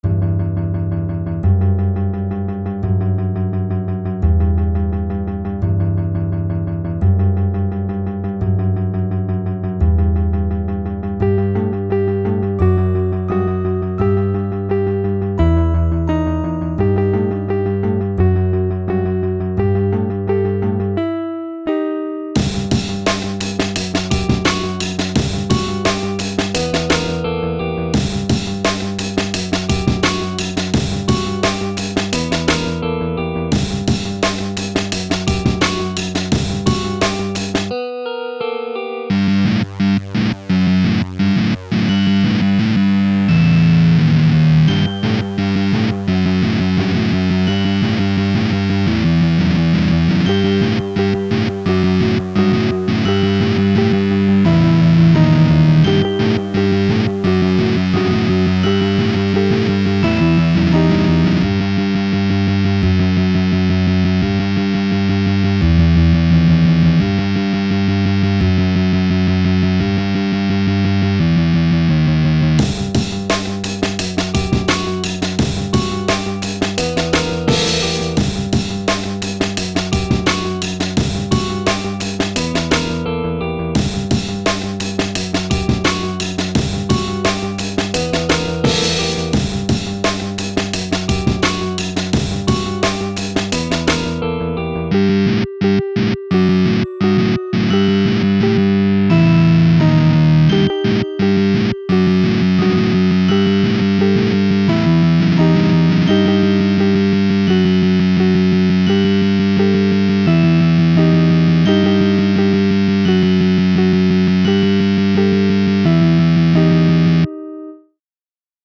Grunge gloomy instrumental thing
electricguitar